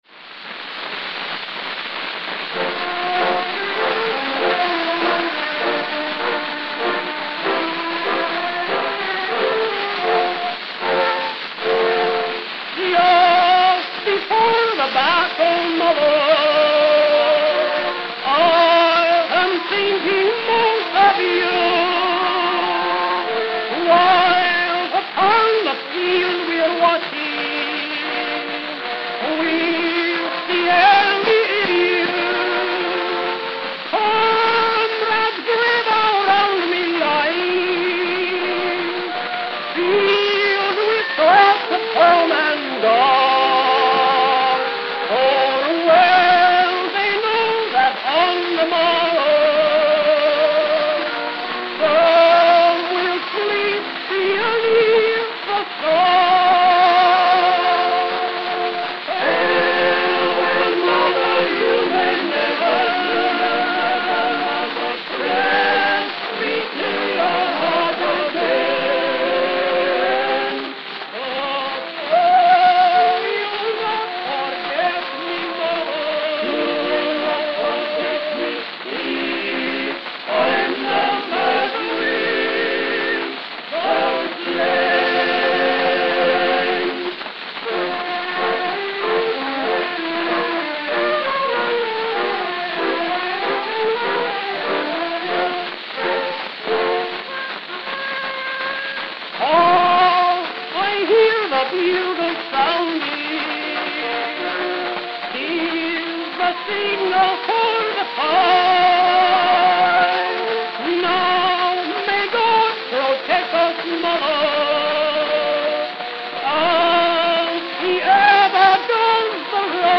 Note: Worn, skip at 0:31.